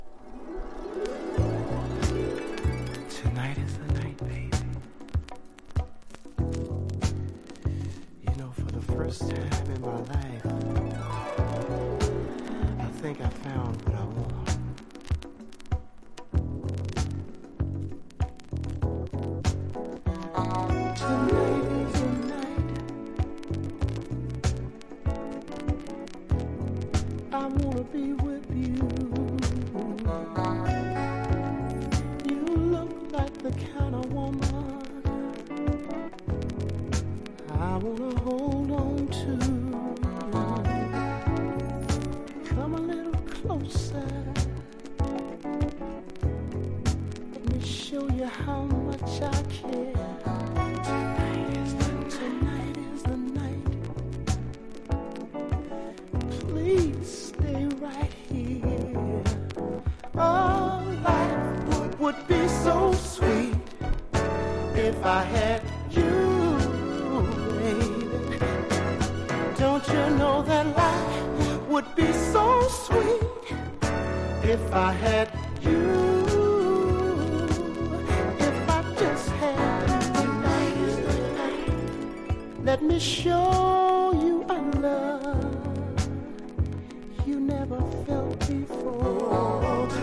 # FUNK / DEEP FUNK